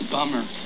Here you will find various sounds taken from Buffy, the Vampire Slayer.